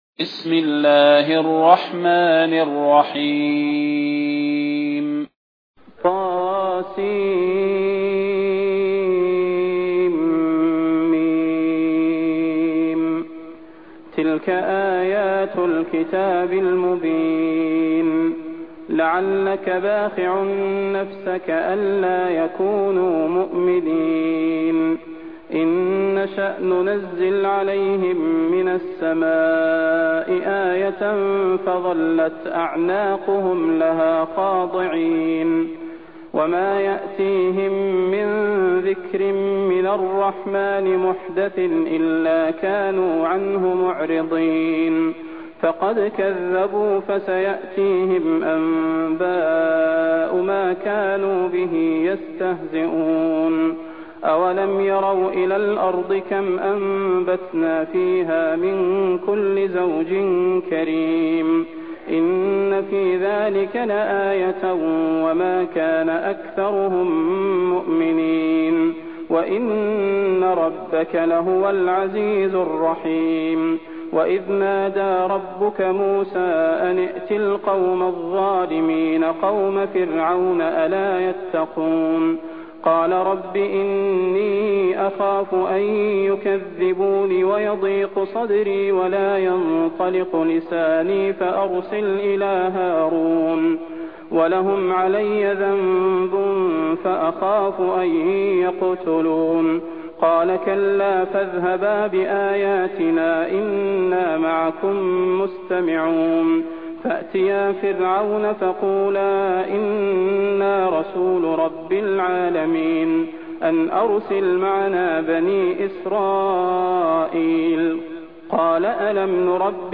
المكان: المسجد النبوي الشيخ: فضيلة الشيخ د. صلاح بن محمد البدير فضيلة الشيخ د. صلاح بن محمد البدير الشعراء